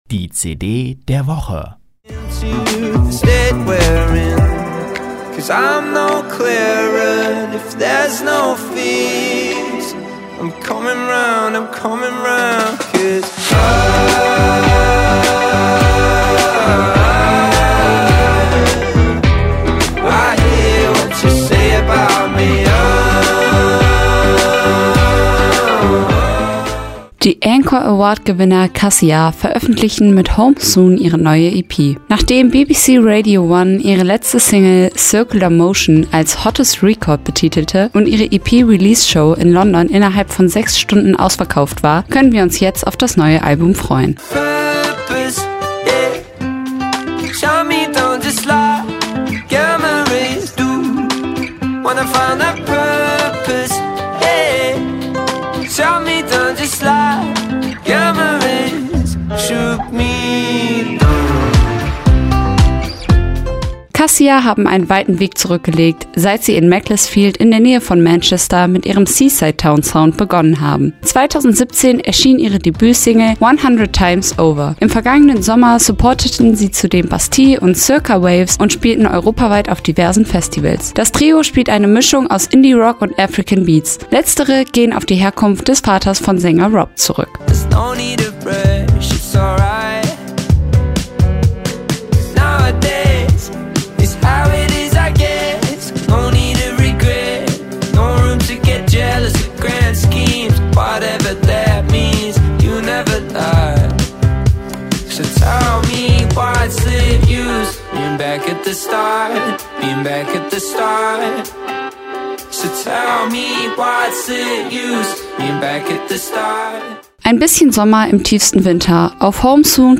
Ein bisschen Sommer im tiefsten Winter.